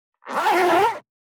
422,ジッパー,チャックの音,洋服関係音,ジー,バリバリ,カチャ,ガチャ,シュッ,パチン,
ジッパー効果音洋服関係